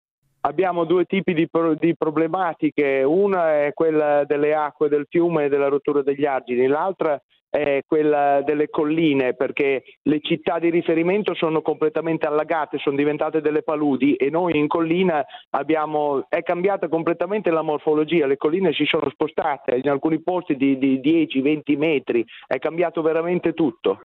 Francesco Billi è il sindaco di Castrocaro Terme: